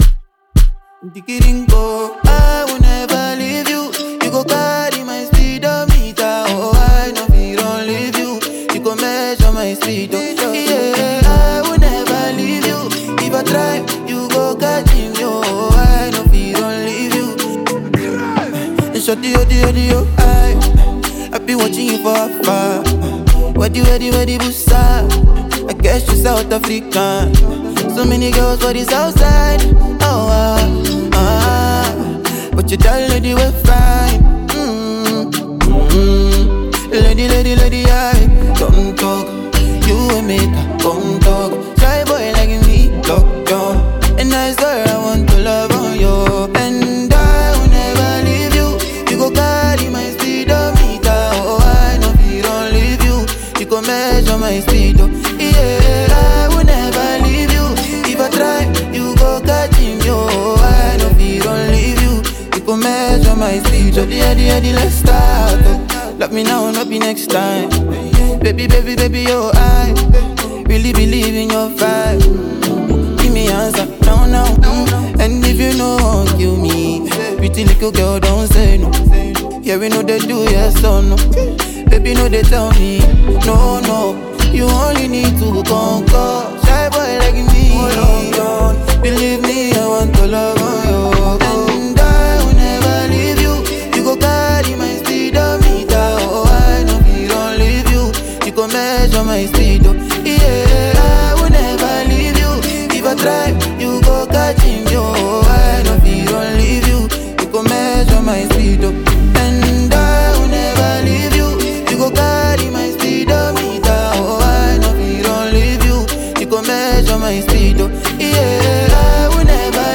Ghanaian singer